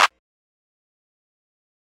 (SXJ) Clap (3).wav